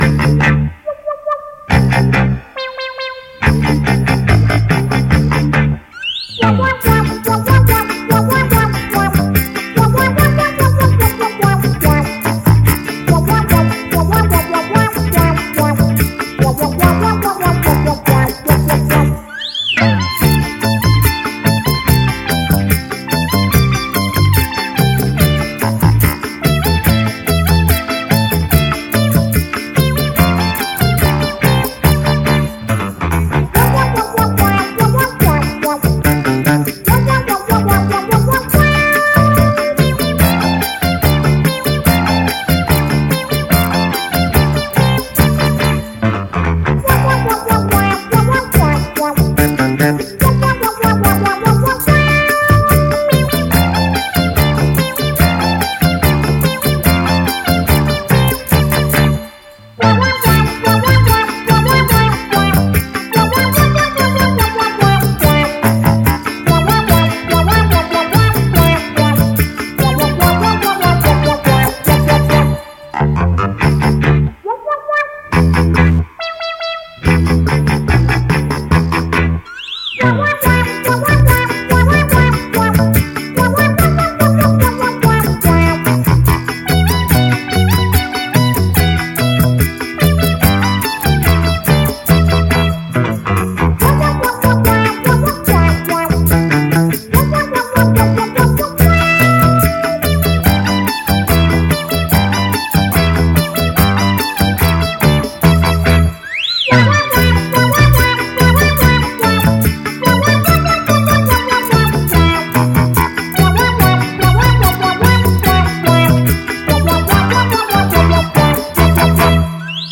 CD 1 Cha Cha 16首轻松的恰恰